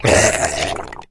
zombie_die_5.ogg